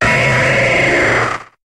Cri de Linéon dans Pokémon HOME.